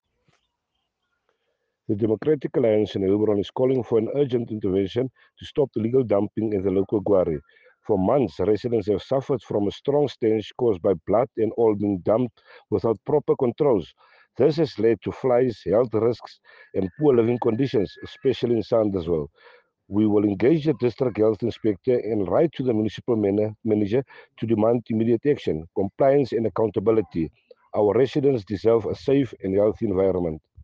English and Afrikaans soundbites by Cllr Robert Ferendale and